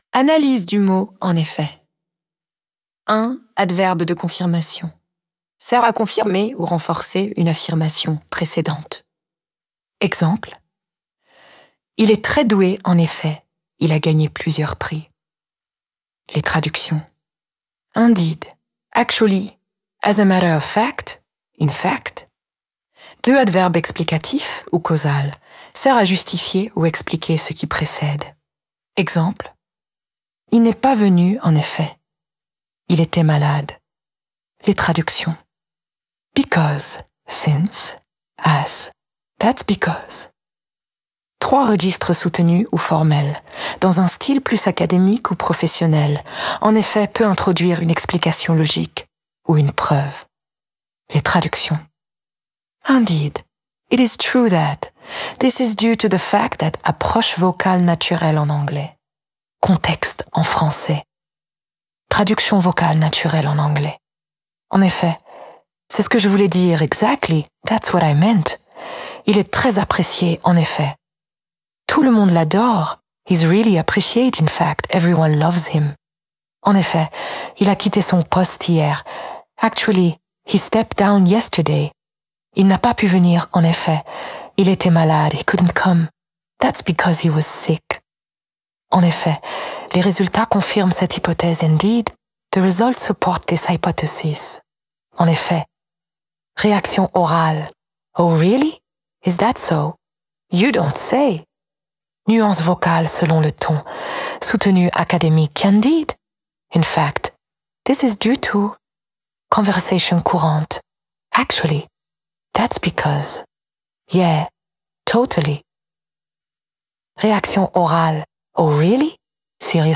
En effet » en anglais – + Audio Prononciation par langage soutenu